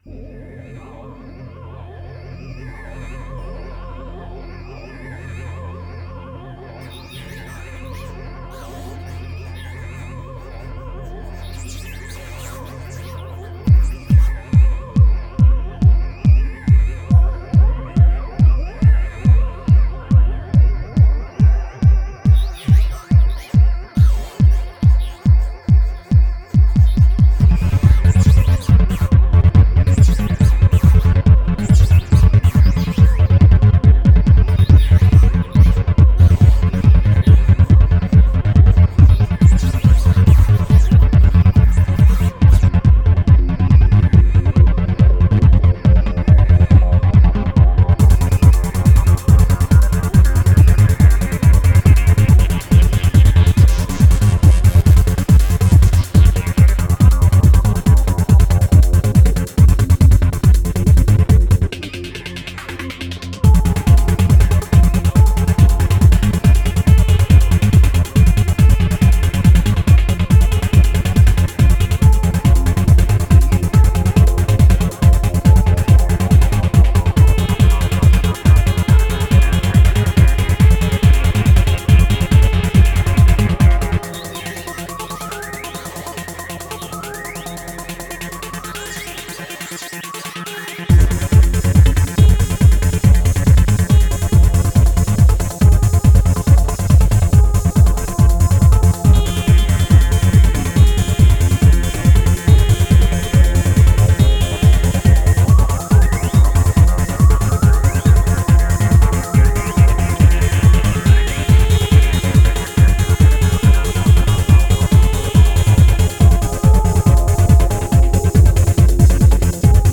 Файл в обменнике2 Myзыкa->Psy-trance, Full-on
Style: Psy-Trance, Goa